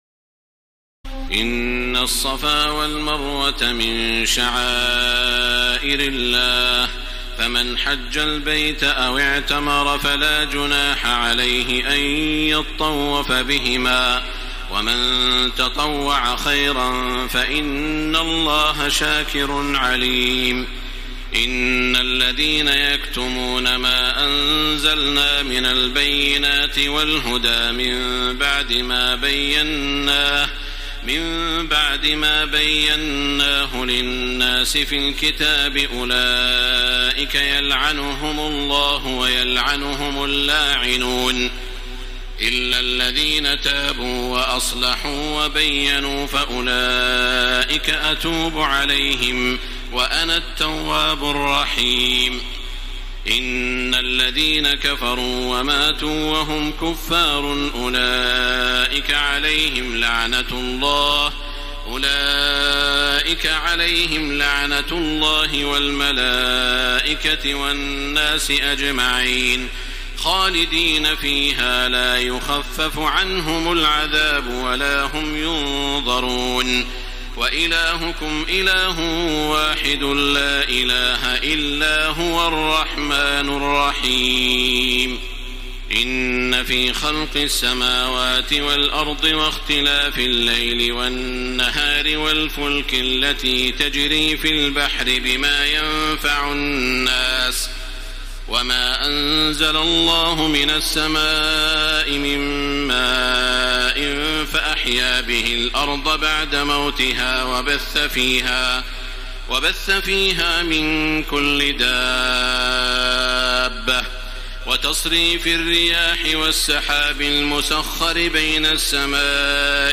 تراويح الليلة الثانية رمضان 1434هـ من سورة البقرة (158-225) Taraweeh 2 st night Ramadan 1434H from Surah Al-Baqara > تراويح الحرم المكي عام 1434 🕋 > التراويح - تلاوات الحرمين